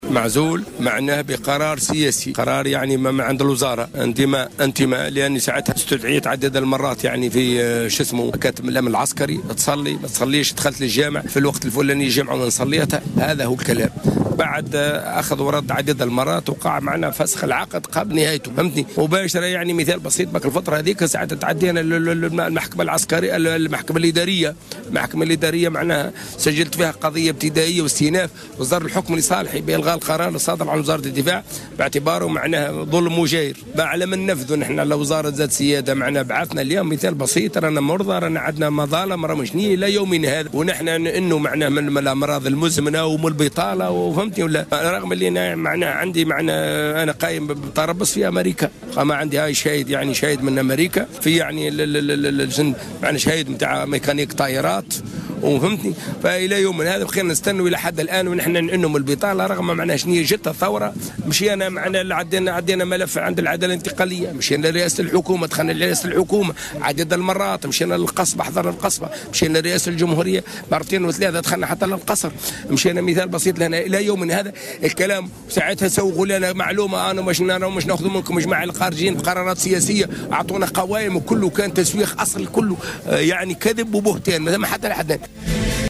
وأكد أحد المحتجين أن قرارات العزل كانت بقرار سياسي و بسبب على أخطاء إدارية بسيطة،وفق تعبيره.